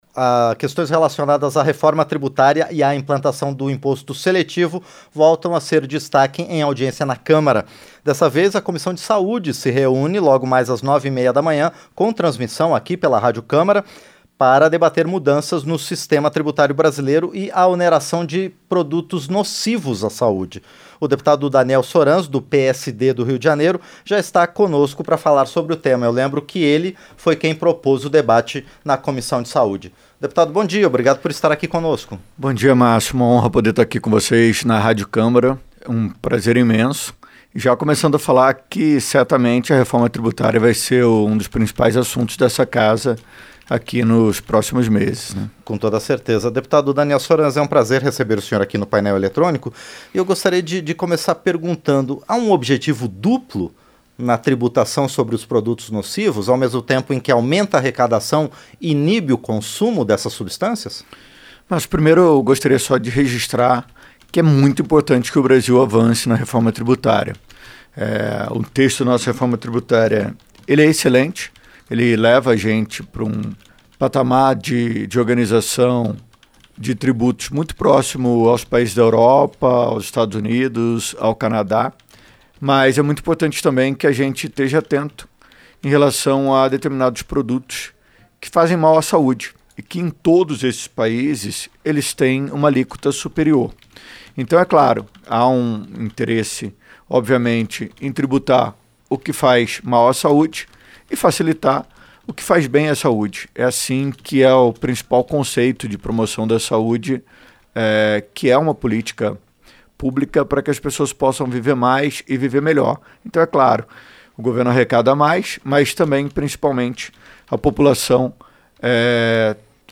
• Entrevista - Dep. Daniel Soranz (PSD-RJ)
Programa ao vivo com reportagens, entrevistas sobre temas relacionados à Câmara dos Deputados, e o que vai ser destaque durante a semana.